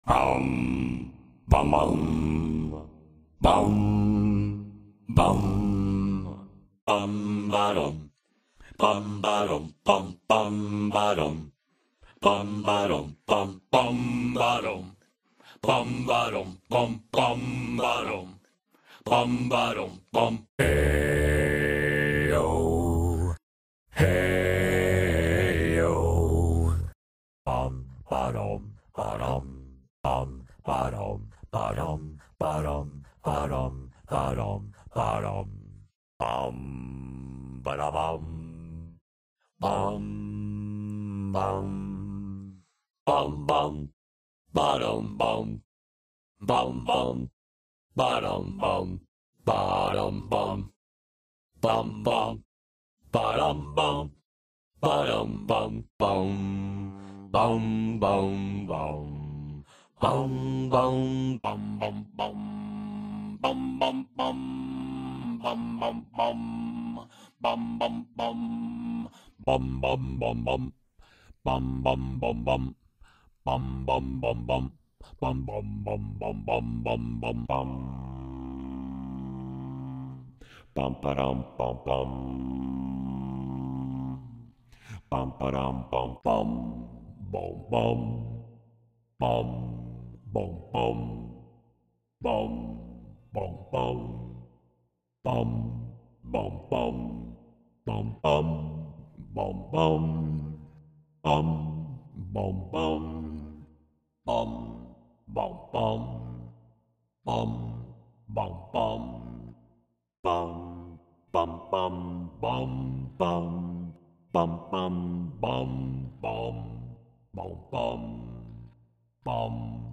Звук пения монстра